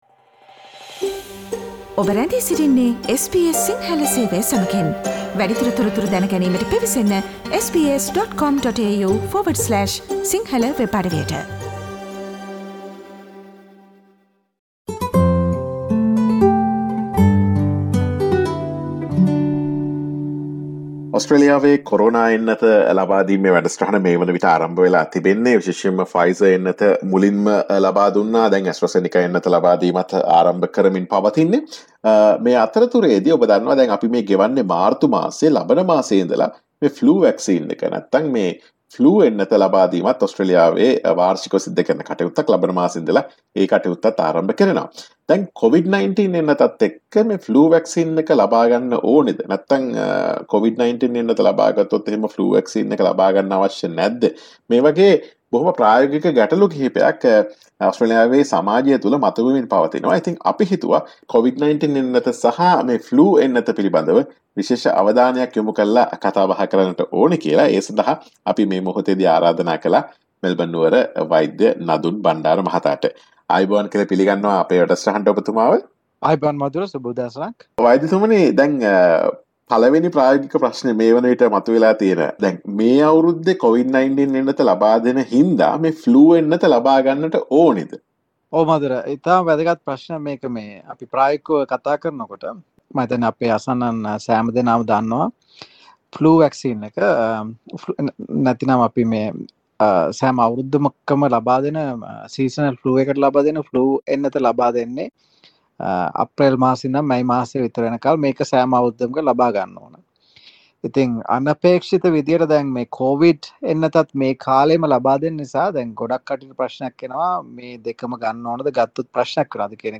ඔස්ට්‍රේලියාවේ මේ වන විට Covid-19 එන්නත ලබාදීම ආරම්භ කර ඇති අතර අප්‍රේල් මස සිට Flu එන්නත ද ලබා දීම ඇරඹේ. මෙම එන්නත් ද්විත්වයම මෙවර ලබාගත යුතුද යන ප්‍රශ්නය පිළිබඳ SBS සිංහල ගුවන් විදුලිය ගෙන එන සාකච්ඡාව.